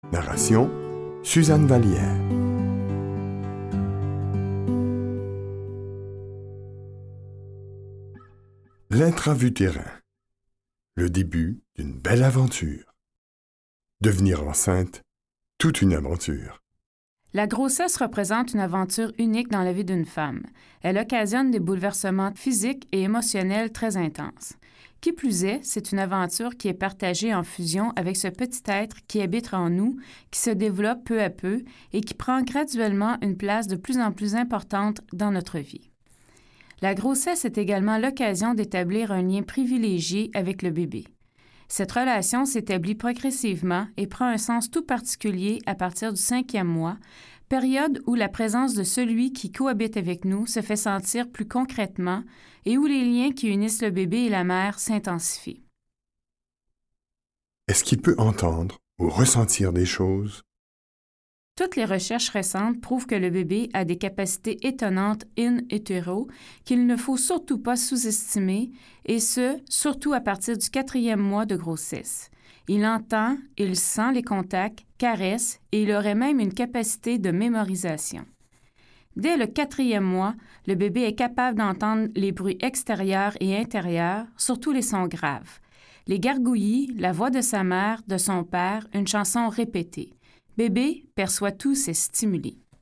Get £2.29 by recommending this book 🛈 Ce livre audio fournit des réponses claires aux questions que beaucoup de parents se posent au sujet du développement de leurs enfants, de la vie intra-utérine jusqu’à l’âge de trois ans. Il s’agit d’un guide concret qui démythifie certaines notions relatives à l’évolution de l’enfant en livrant, le plus simplement possible, trucs et conseils qui faciliteront la vie des parents.